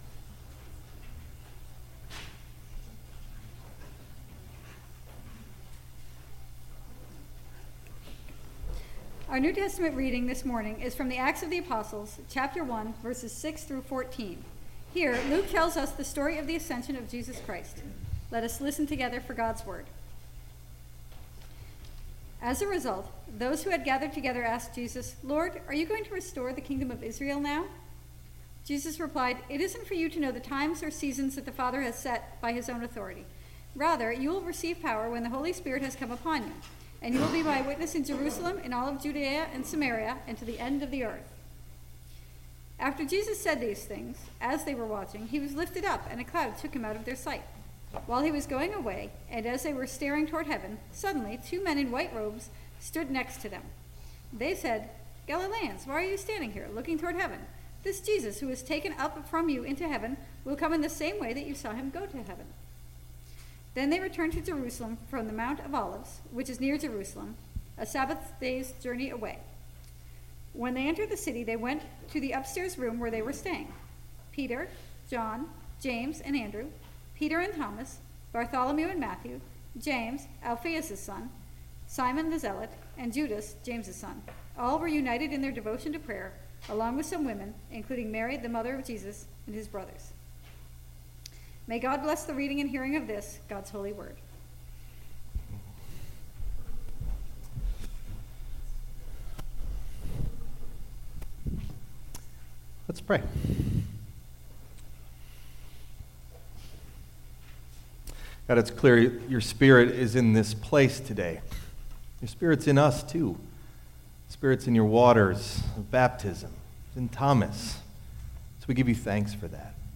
Message Delivered at: The United Church of Underhill (UCC & UMC)